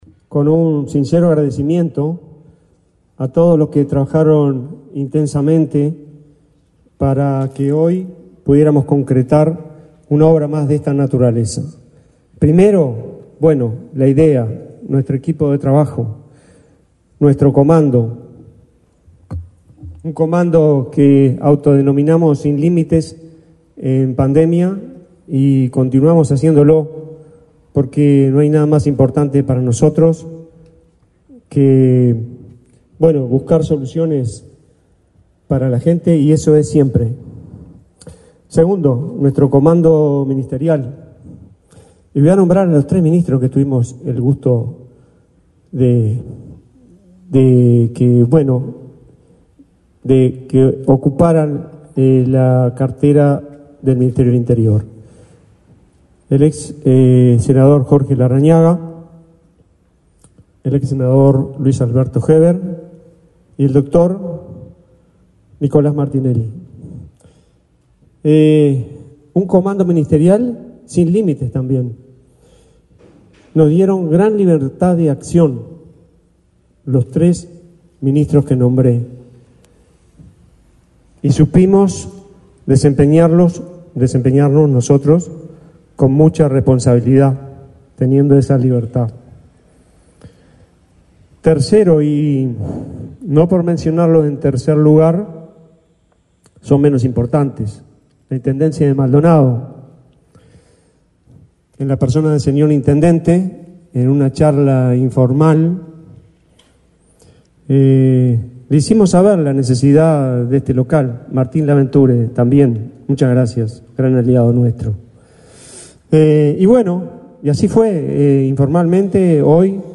Palabras del director nacional de Migración, Eduardo Mata
Palabras del director nacional de Migración, Eduardo Mata 25/02/2025 Compartir Facebook X Copiar enlace WhatsApp LinkedIn El Ministerio del Interior inauguró, este 25 de febrero, una oficina de Migración en Punta del Este, Maldonado. En el evento, el director nacional de Migración, Eduardo Mata, realizó declaraciones.